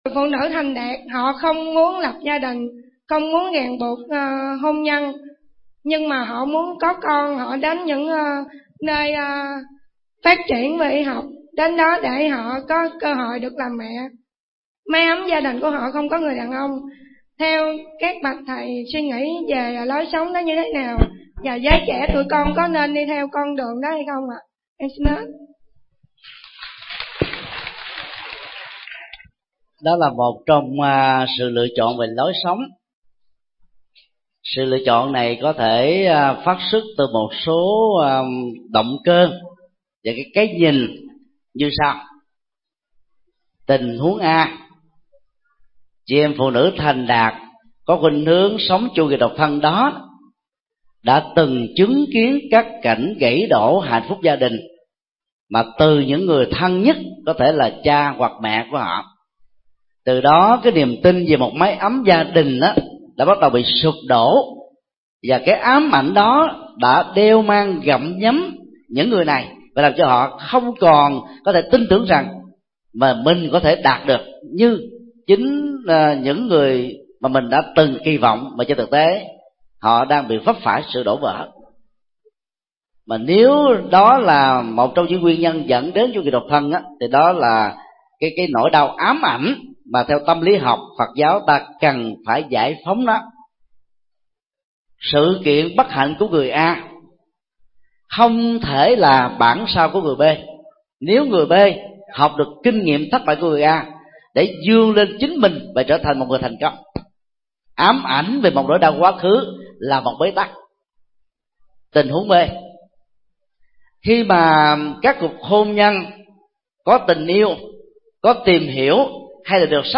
Vấn đáp: Chủ nghĩa độc thân, sinh con đơn thân – Thích Nhật Từ